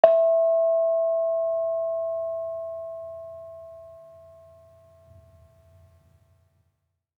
Bonang-E4-f.wav